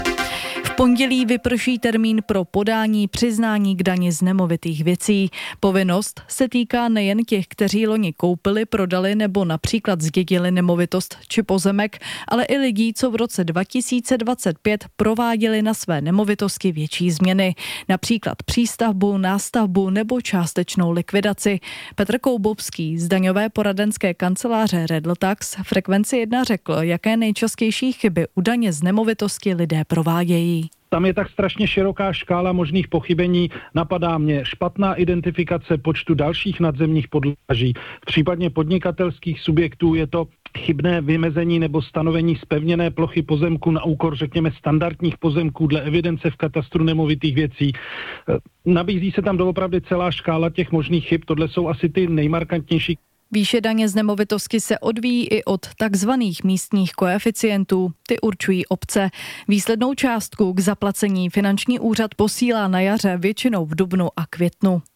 Daň z nemovitostí: Rozhovor